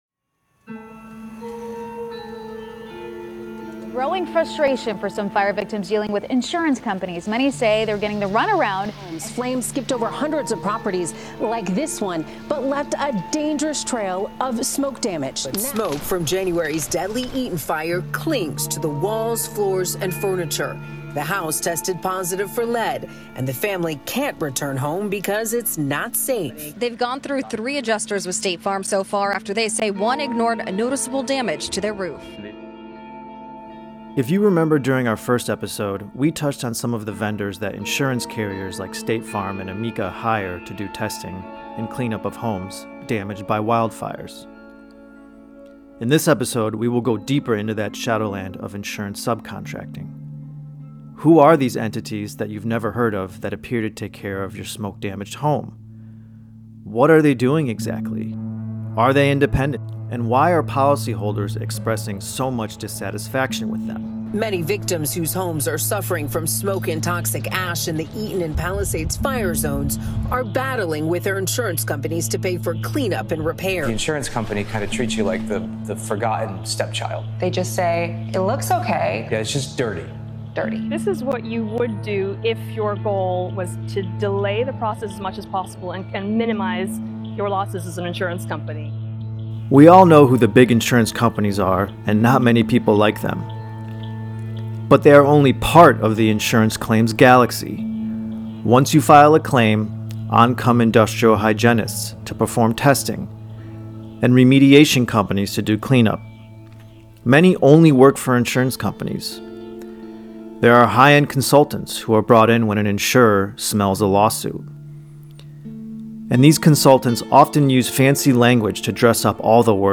You’ll be hearing from fire survivors who have fought and won, public adjusters who seek to get claims paid on behalf of policyholders, attorneys who litigate in court against insurance companies, and experts in toxins and testing.